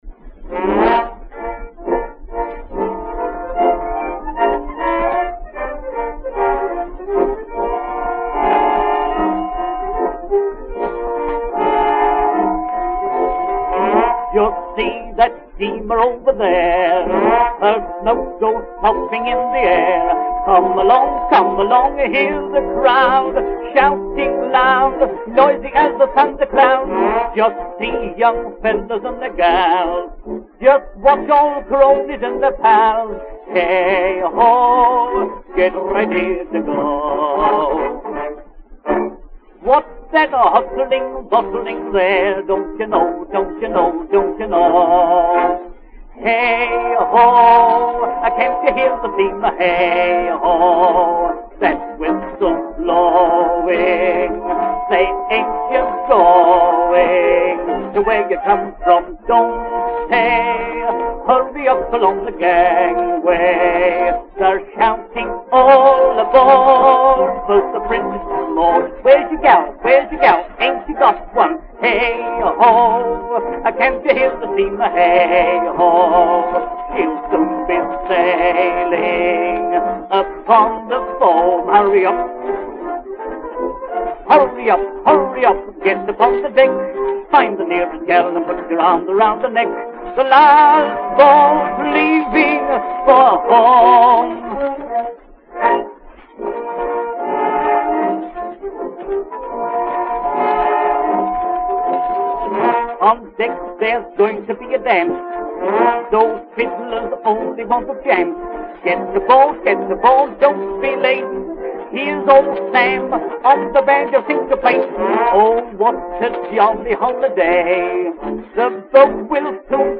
An extremely popular number in what passed in England for “ragtime” style, which was all the rage just before the war.